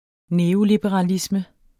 Udtale [ ˈneːolibəʁɑˌlismə ]